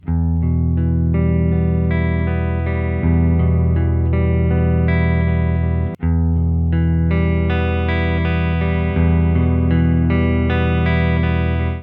Ein Bekannter bat mich noch Vorher-Nachher Aufnahmen zu machen, welches ich heute tat.
Den angehängten Soundschnipsel hab ich mit dem Halspickup aufgenommen und für den Vergleich die alten, angelaufenen D'Addario EXL110 verwendet. Das original Tailpiece bringt 88g, das Faber Alu Tailpiece nur 27g auf die Waage.